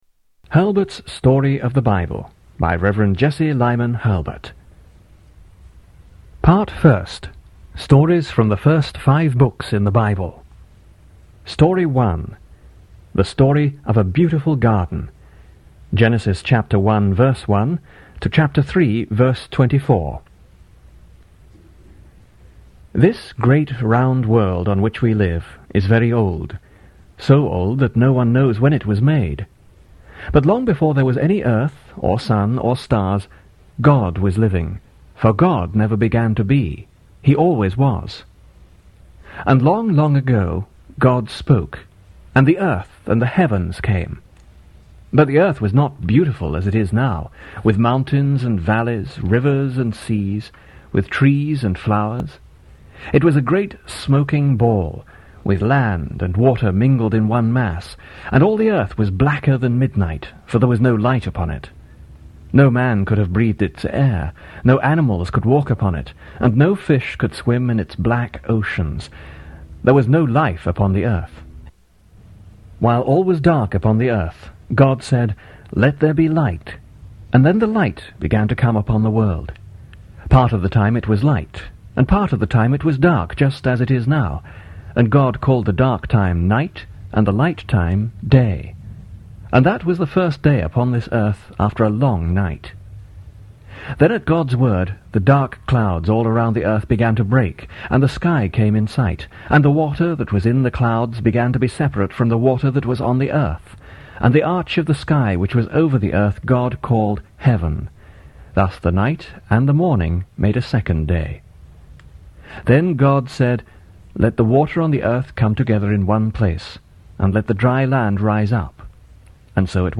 Tags: Christian Books Audio books Christian Audio books Media